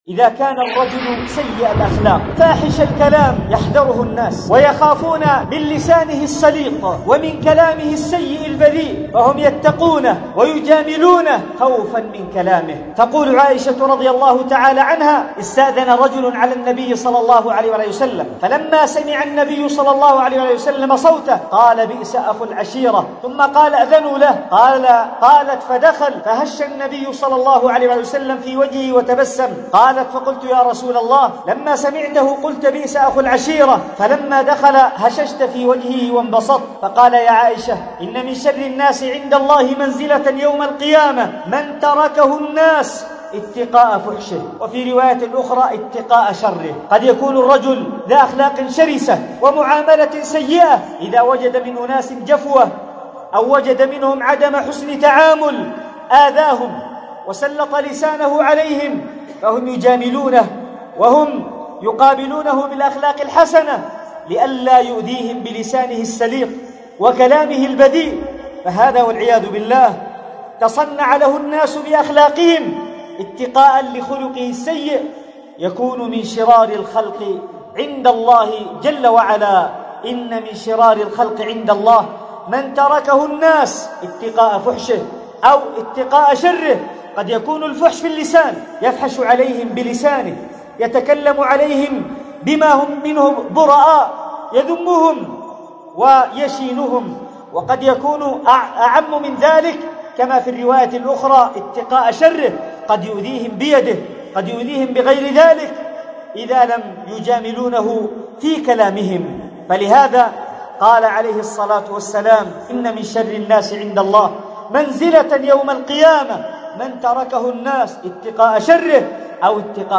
تم قص المقطع من خطبة شرار الناس